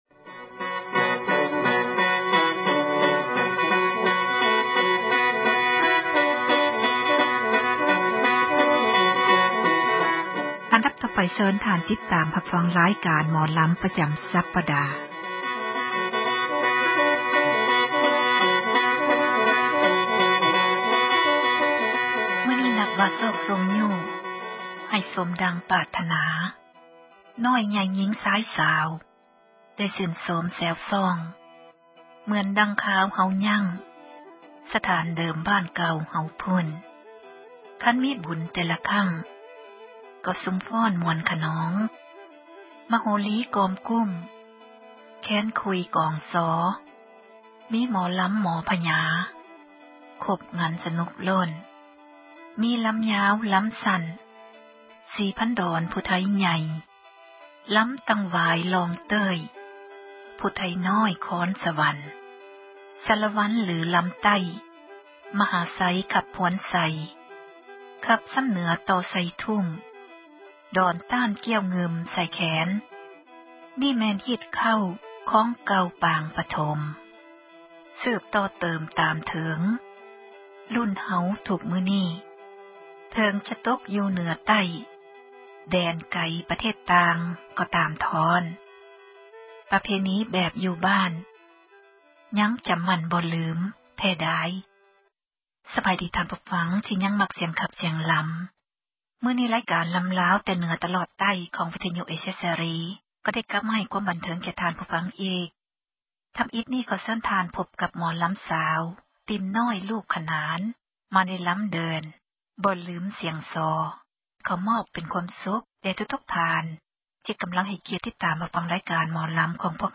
ສິລປະ ການຂັບລໍາ ຂອງ ເຊື້ອສາຍ ລາວ ໃນແຕ່ລະ ຊົນເຜົ່າ ແຕ່ລະ ພື້ນເມືອງ. ເຮົາເອົາມາ ສູ່ກັນຟັງ ເພື່ອ ຄວາມບັນເທີງ ແລະ ຊ່ອຍກັນ ສົ່ງເສີມ ອະນຸຮັກ ໄວ້ໃຫ້ ລູກຫລານ ໃນ ພາຍ ພາກໜ້າ.
ໝໍແຄນ ເປົ່າ